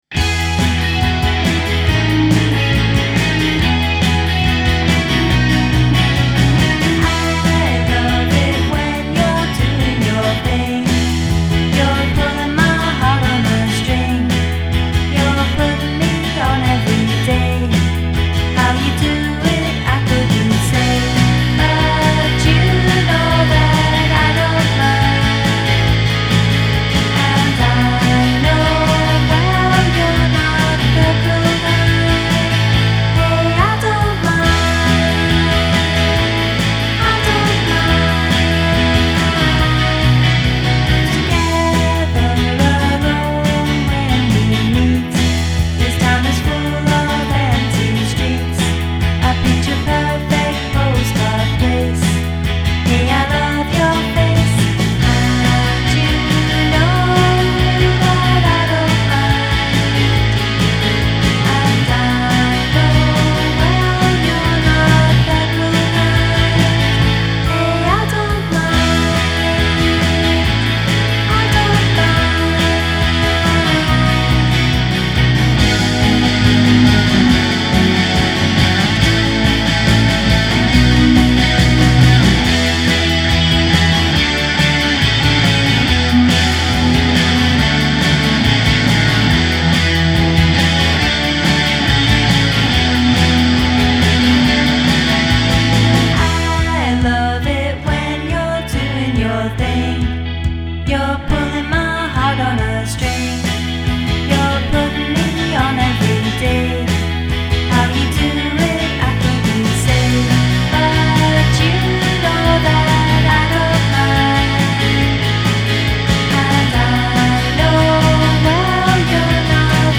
con quel sorriso e la primavera nelle chitarre.